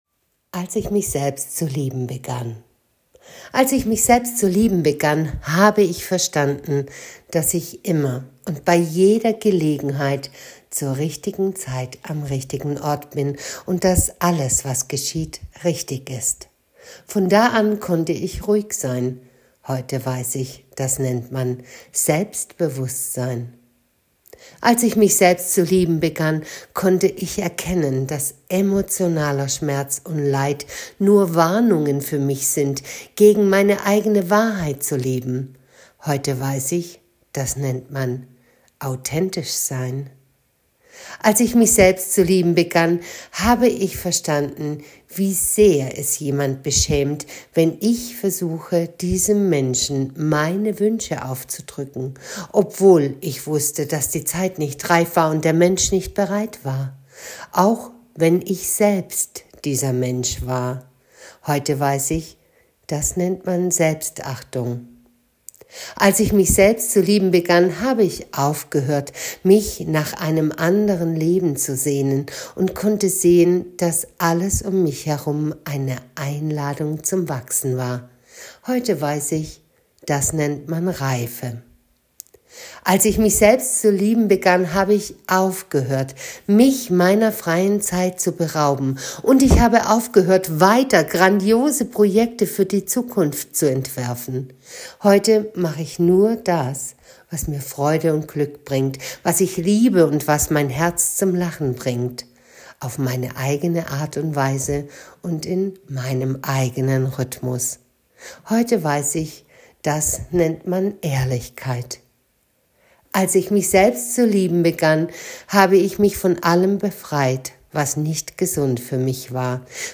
Gedicht zum Anhören: Als ich mich selbst zu lieben begann